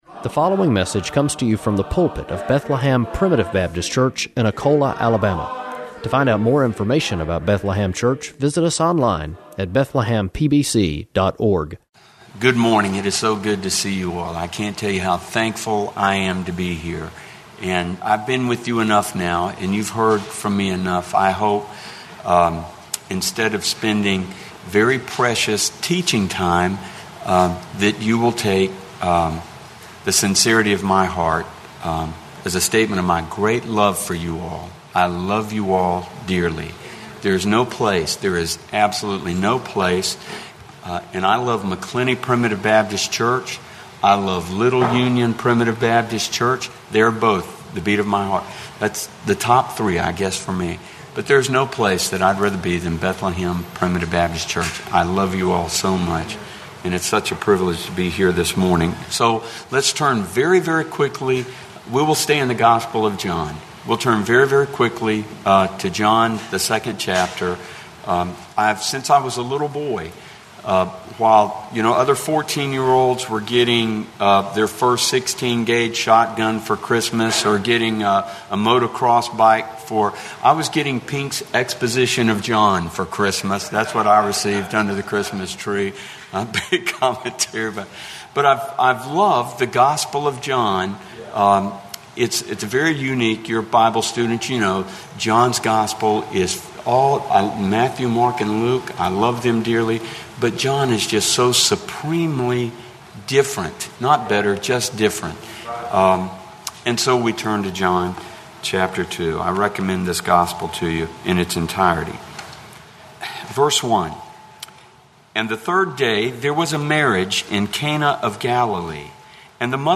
Preached August 20, 2017 http